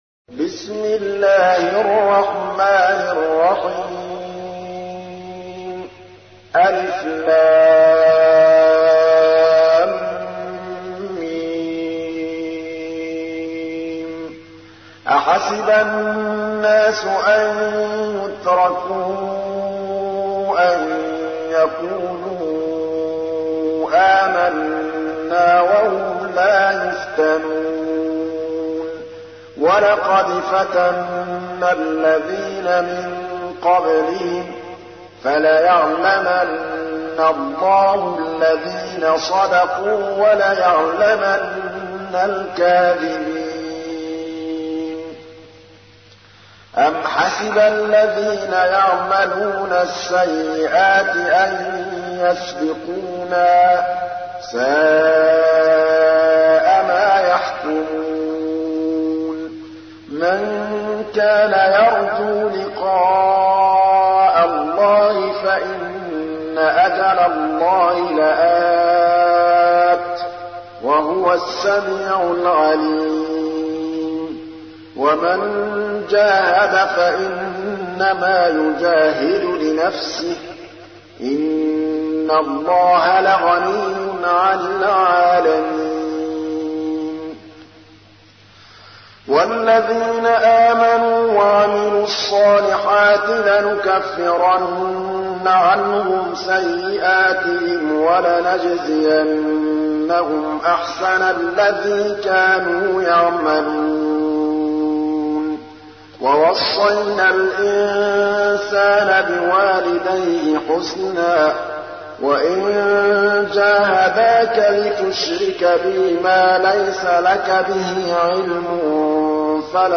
تحميل : 29. سورة العنكبوت / القارئ محمود الطبلاوي / القرآن الكريم / موقع يا حسين